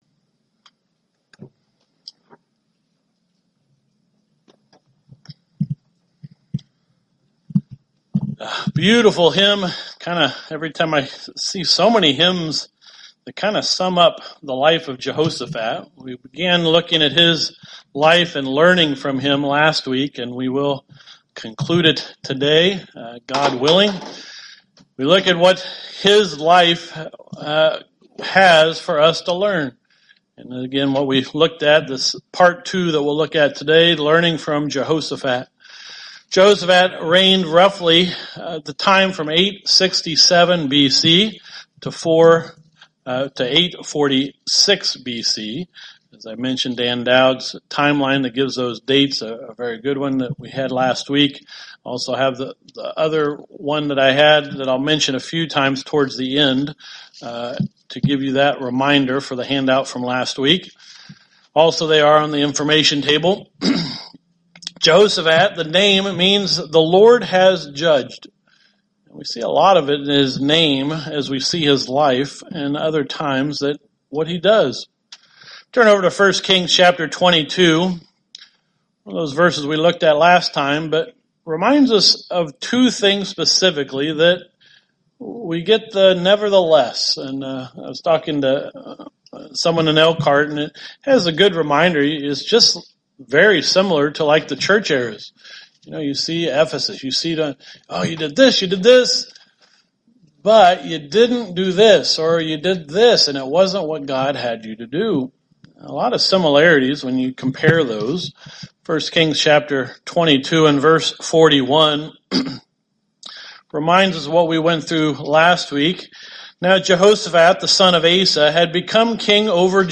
Given in Elkhart, IN Northwest Indiana